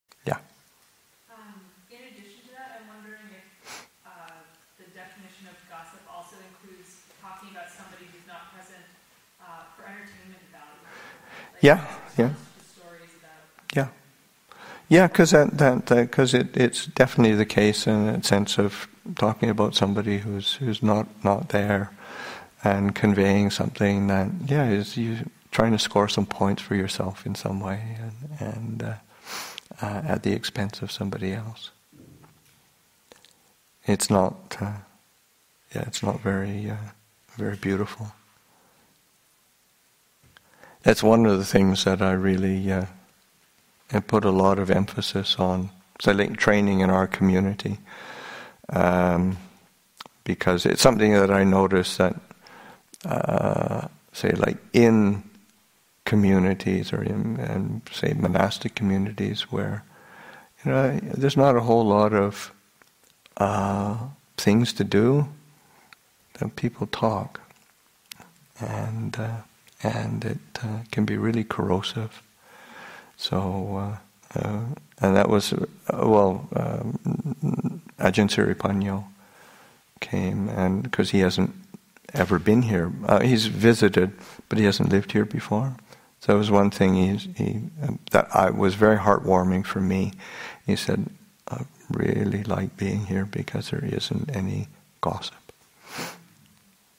Teen Weekend 2017, Excerpt 19